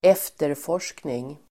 Uttal: [²'ef:terfår_s:kning]